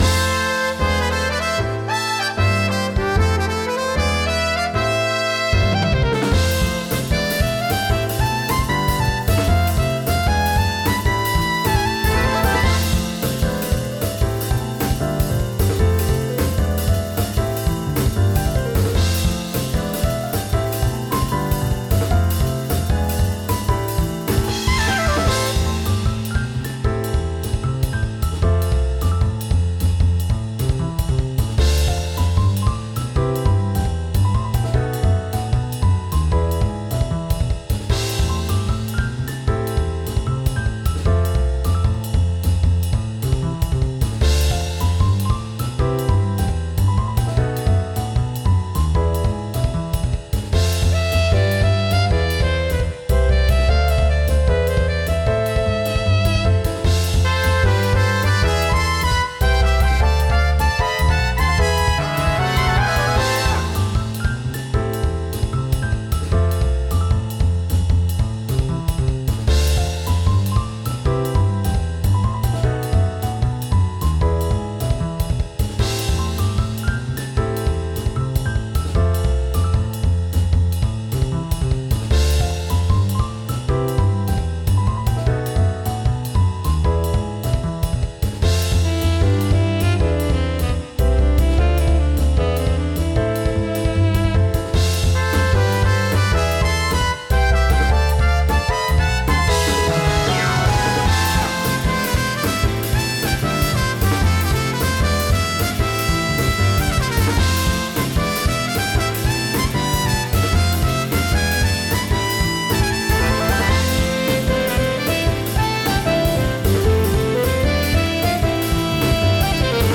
フリーBGM素材- なんかいろんな属性を扱う魔法使い！って感じの爽やかめ戦闘曲。